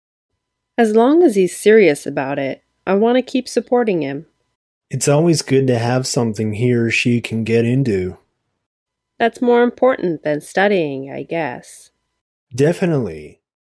Step 4 ③(slow)
Lesson06_Step4_3_slow.mp3